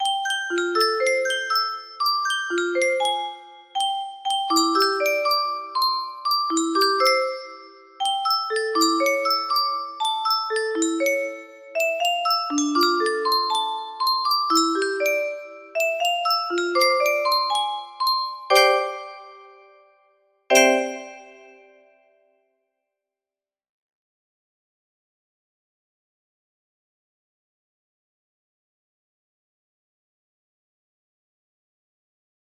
Alexandra 2 music box melody
Grand Illusions 30 (F scale)